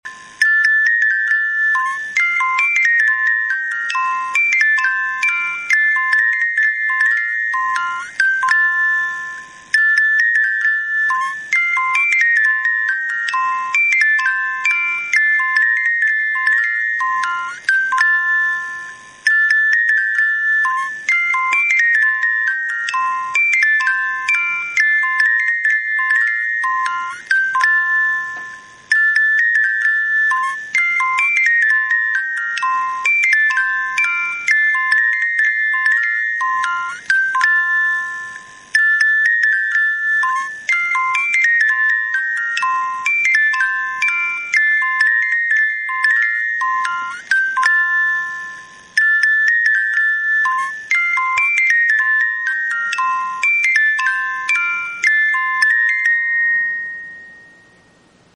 Cigarrera musical
En esa ocasión descubrí una pequeña caja azul con un grabado de flores en dorado, pedí me la enseñaran, era una cigarrera hecha en Japón, al abrirla descubrí que era musical; pregunte el precio pero no me alcanzaba para llevarmela en ese momento.
Es por eso que ahora comparto su sonido, en homenaje a esos objetos curiosos que, al menos para mí, jamás pense que existieran.